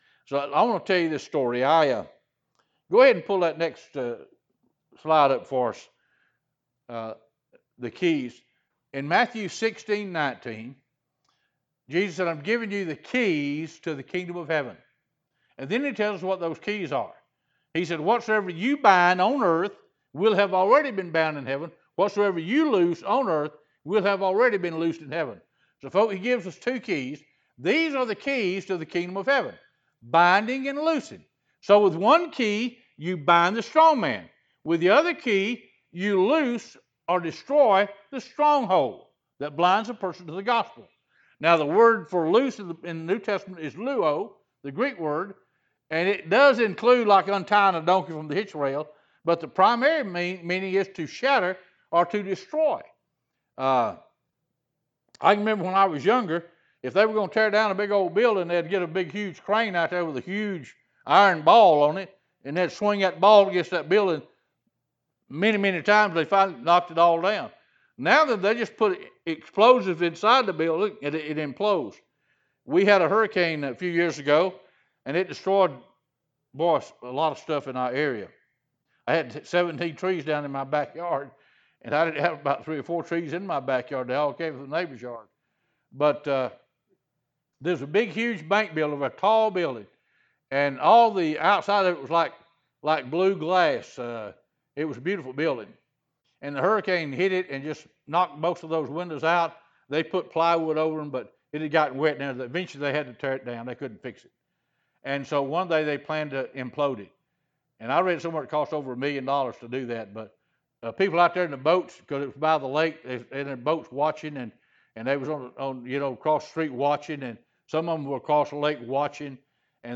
Service Type: Teachings